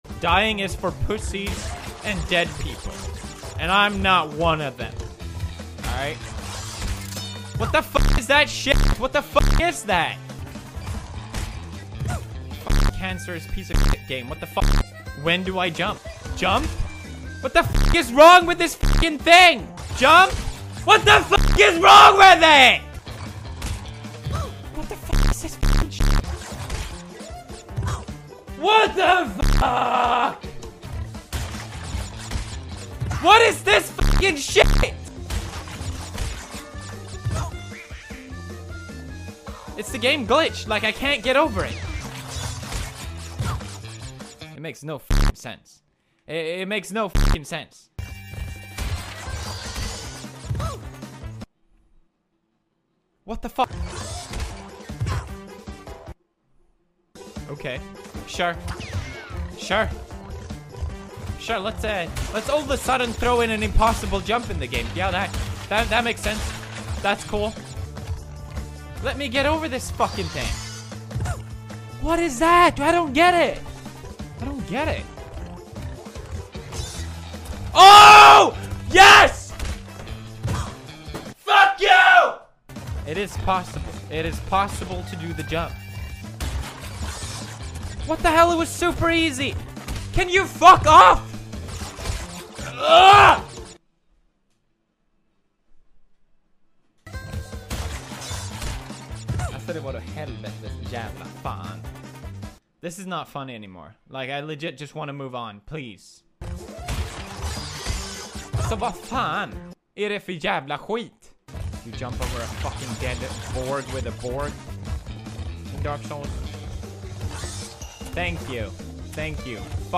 PewDiePie mad at a game sound effects free download